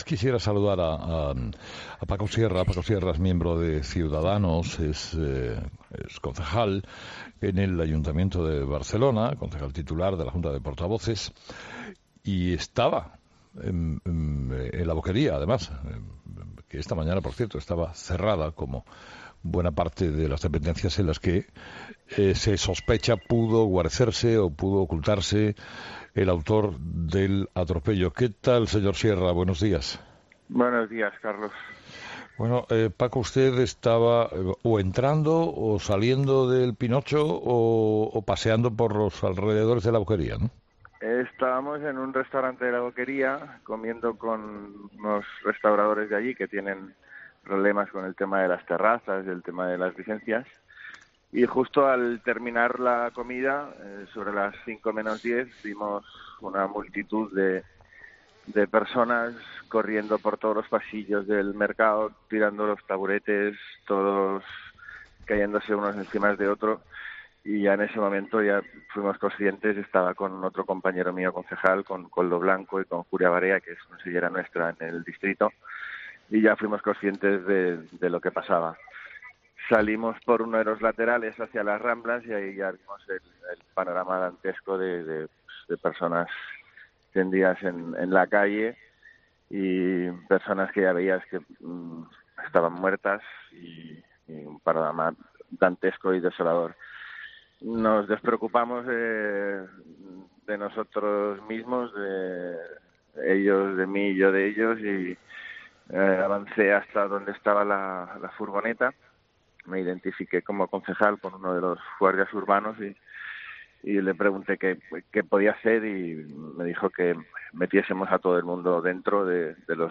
Escucha a Paco Sierra, miembro de Ciudadanos y concejal del ayuntamiento de Barcelona. Vivió en primera persona el atentado de Barcelona